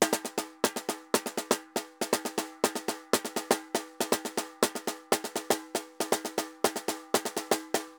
Tambor_Candombe 120_2.wav